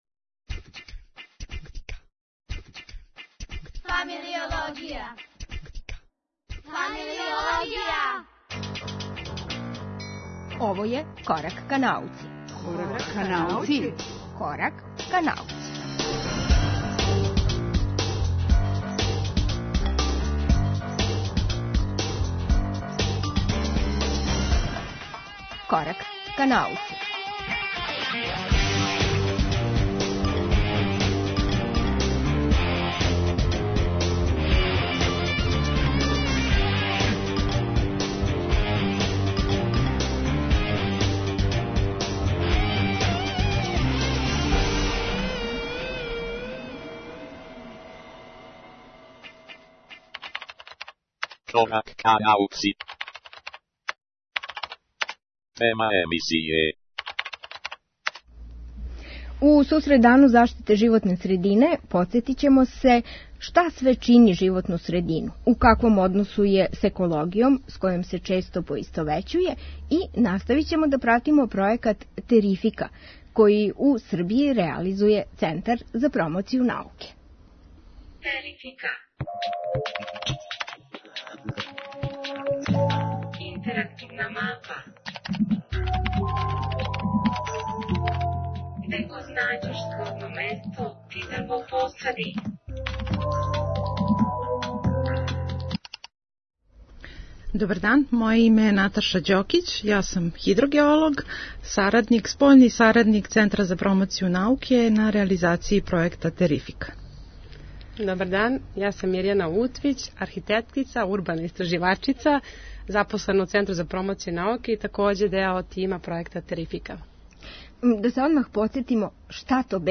У сусрет Дану заштите животне средине о различитим аспектима климатских промена разговарамо са реализаторима пројекта TeRIFICA.